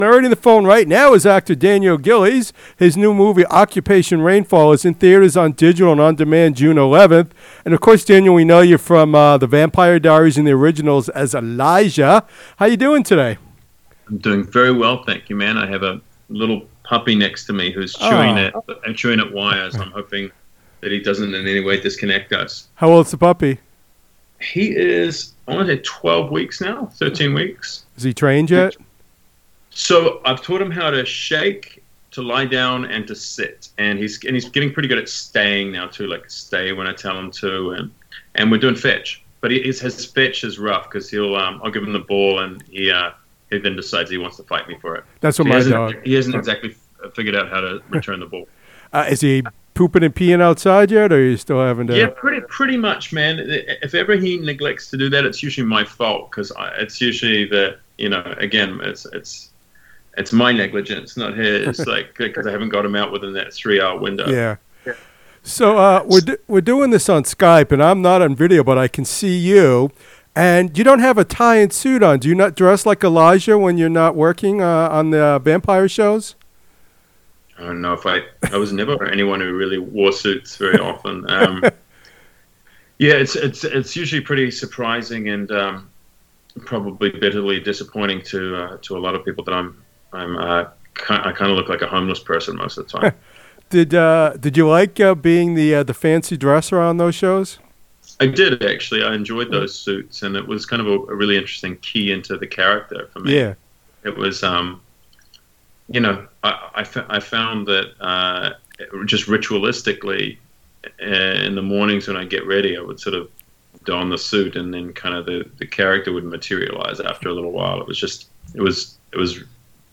Watch Trailer For ‘Occupation: Rainfall’ In Theaters, Digital And On Demand Friday, June 11 And Listen To Interview With Star Daniel Gillies